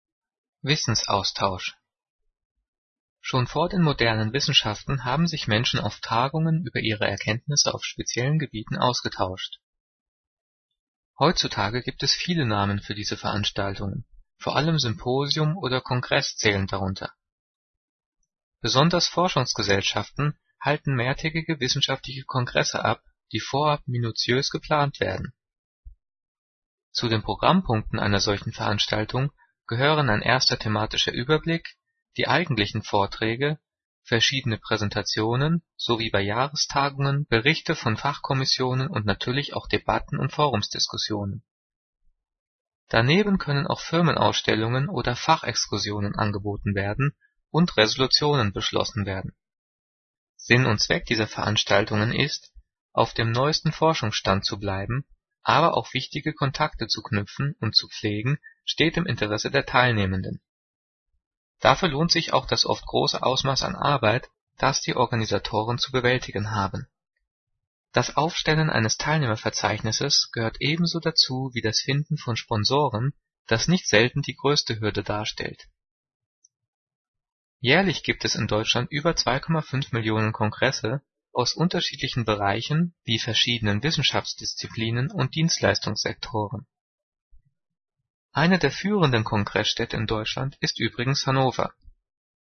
Diktat: "Wissensaustausch" - 9./10. Klasse - s-Laute
Gelesen: